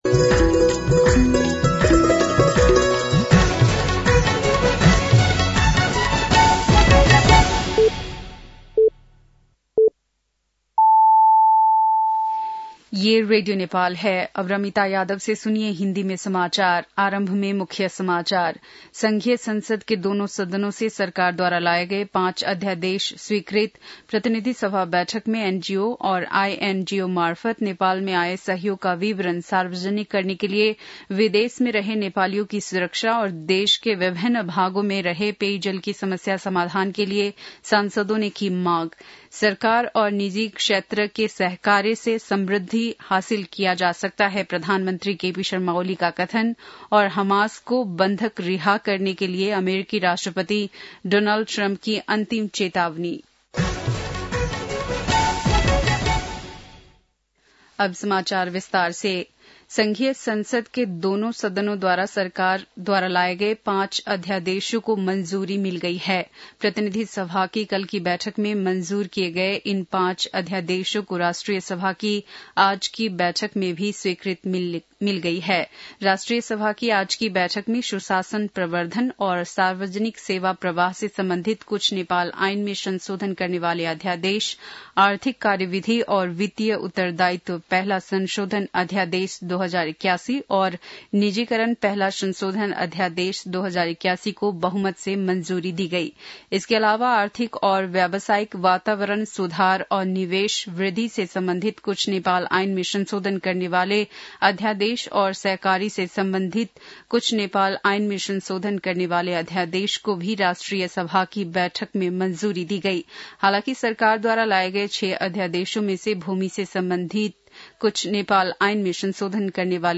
बेलुकी १० बजेको हिन्दी समाचार : २३ फागुन , २०८१